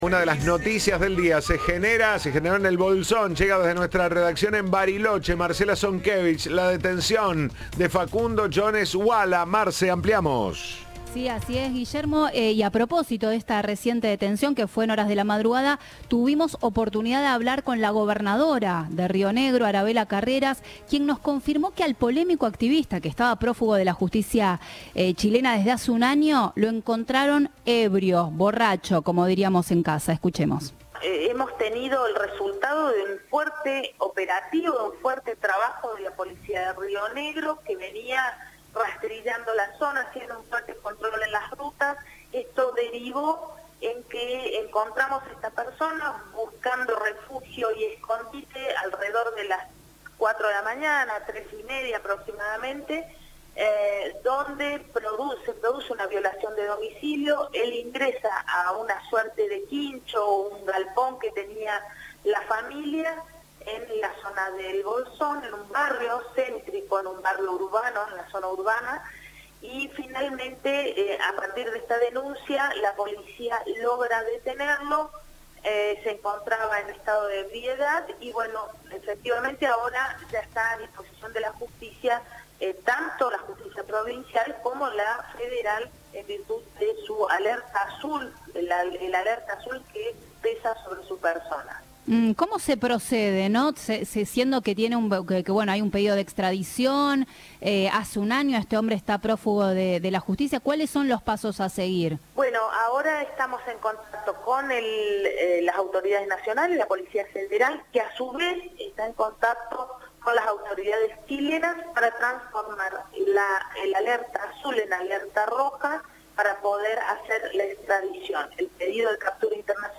Audio. La gobernadora de Río Negro confirmó que estaba alcoholizado